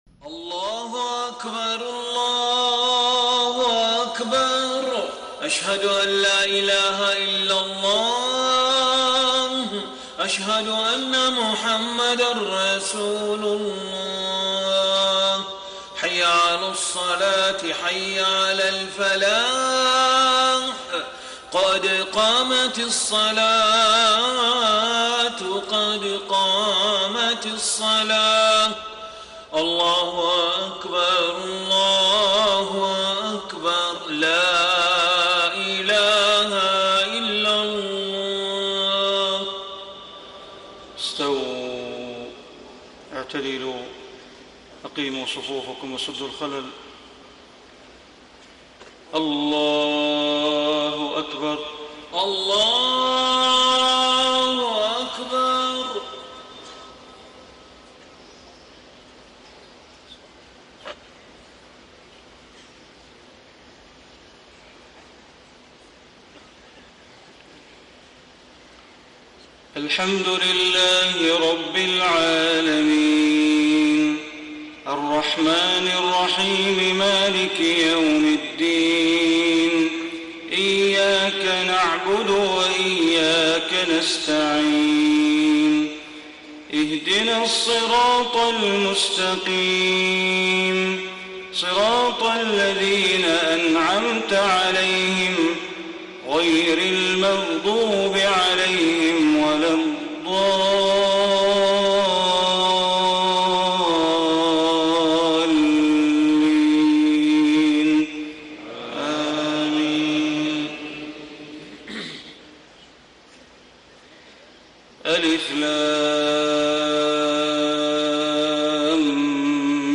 صلاة الفجر الجمعة 5 - 1 - 1435هـ سورتي السجدة و الإنسان > 1435 🕋 > الفروض - تلاوات الحرمين